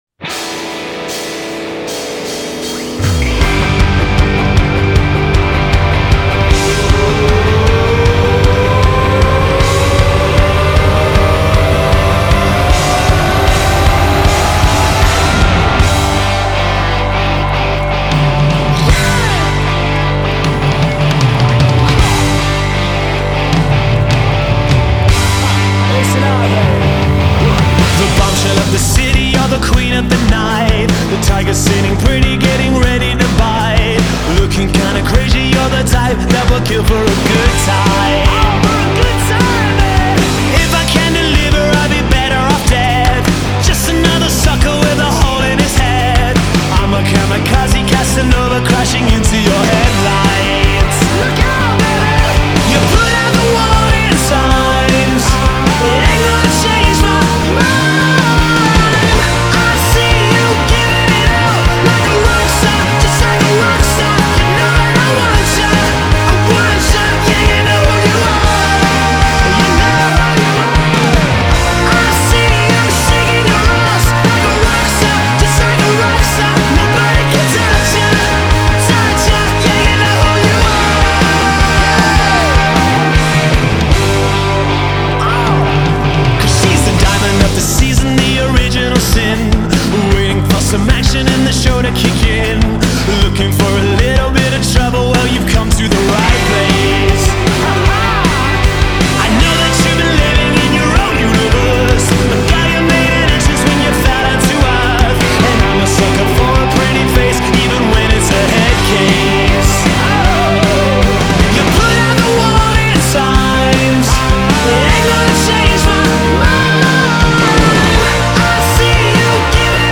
British rock band